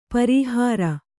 ♪ parīhāra